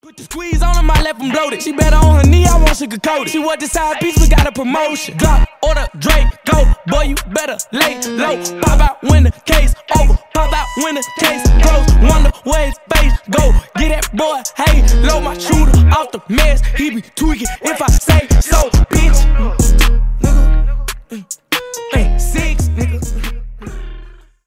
Рэп и Хип Хоп
злые